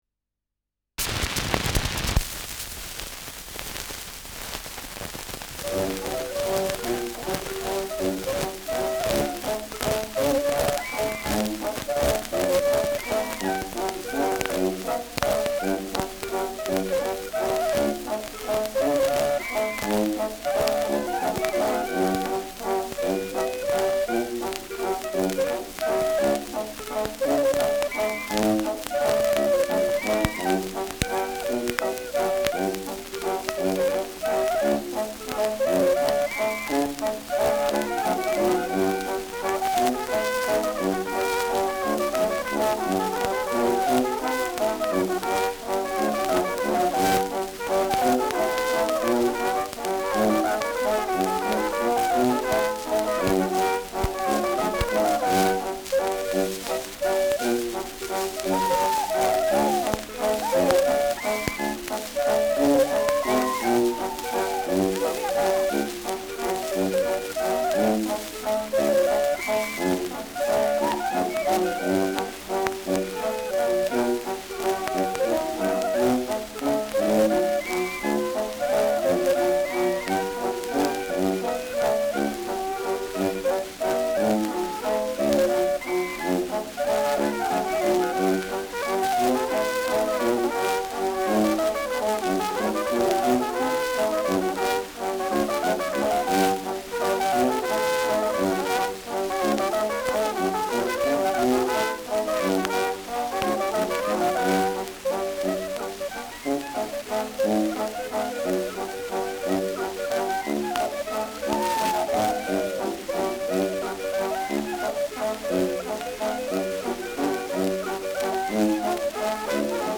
Schellackplatte
Starkes Grundrauschen : Nadelgeräusch : Durchgehend leichtes bis starkes Knacken